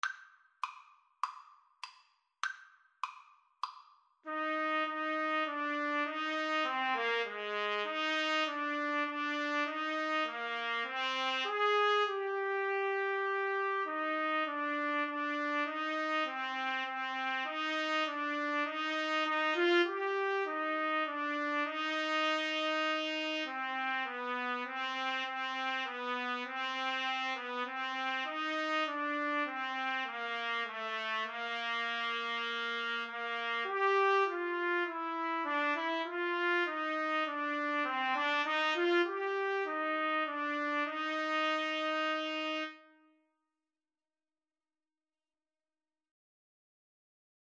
Eb major (Sounding Pitch) F major (Trumpet in Bb) (View more Eb major Music for Trumpet Duet )
4/4 (View more 4/4 Music)
Trumpet Duet  (View more Easy Trumpet Duet Music)
Classical (View more Classical Trumpet Duet Music)